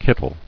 [kit·tle]